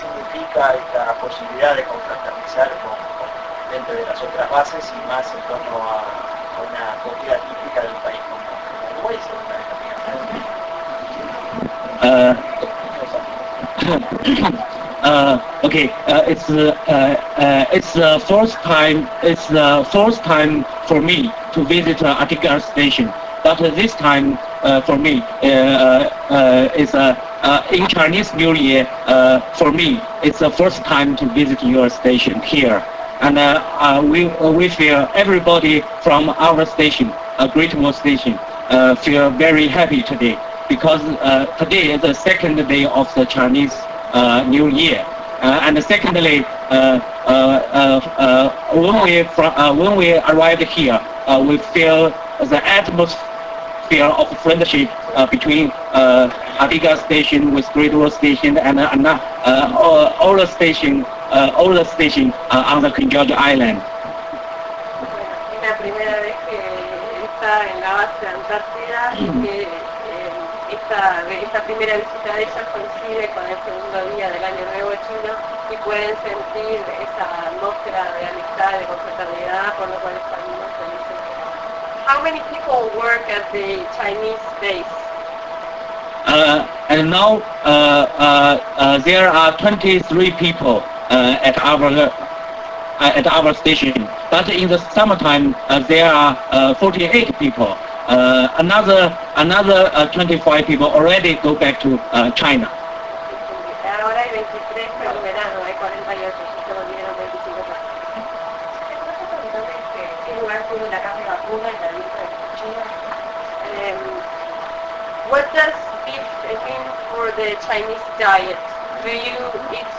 Representante de estación de